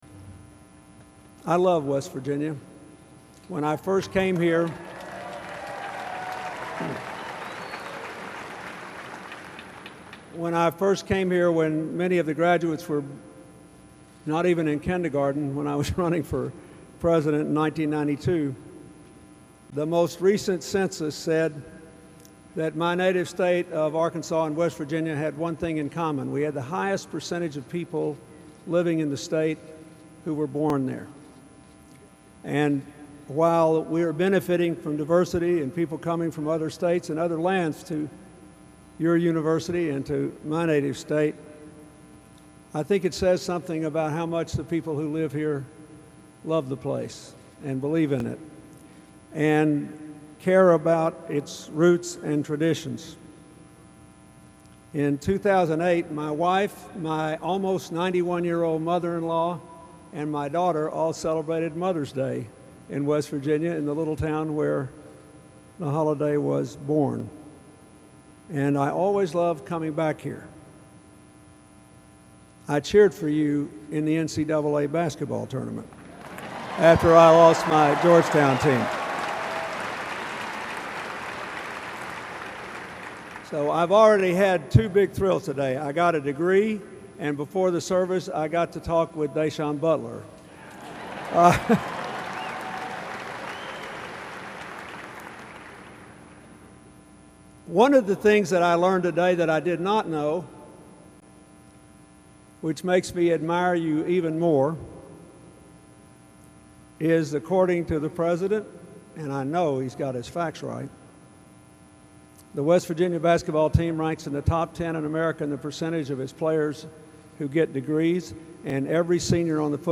Click below to hear President Clinton talk about why he loves West Virginia: